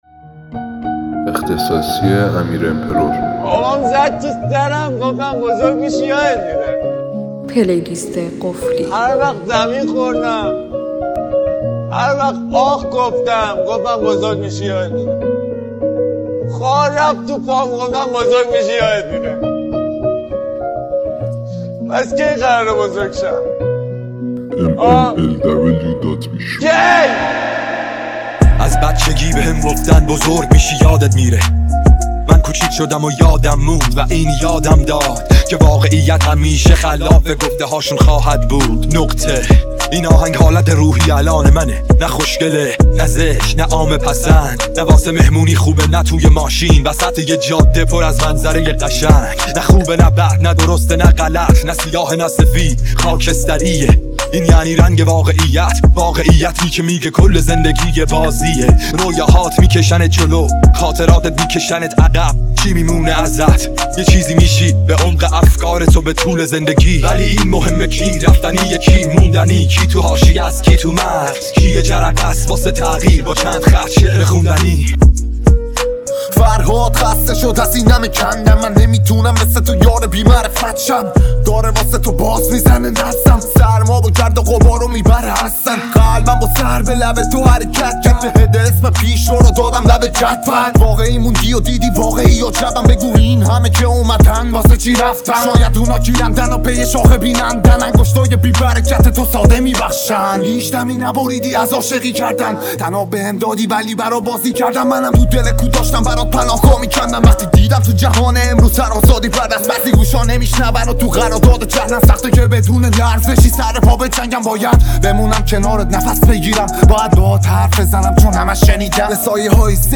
ریمیکس ترکیبی رپ